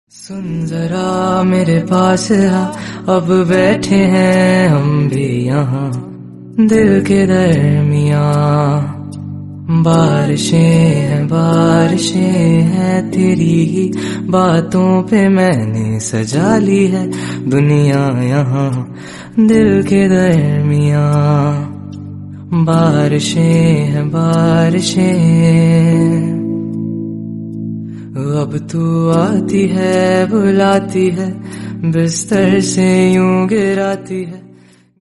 soothing instrumental version